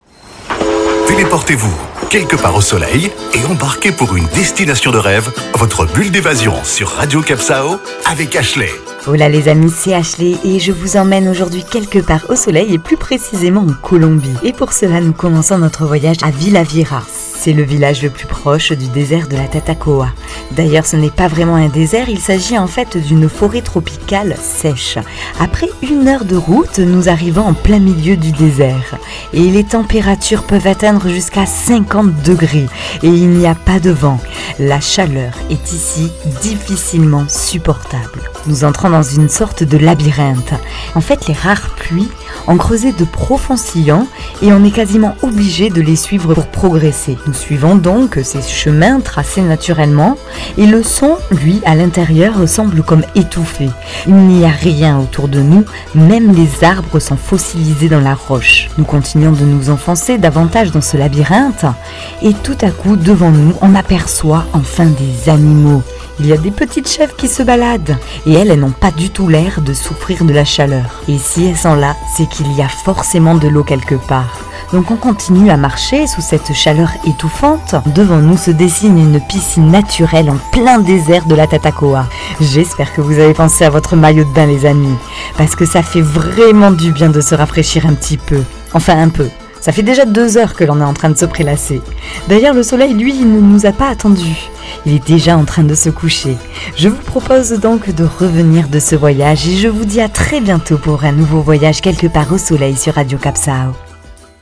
Carte postale sonore : préparez-vous pour une destination aride et labyrinthique, dans un silence étouffant, le long des sillons naturels et des arbres fossilisés.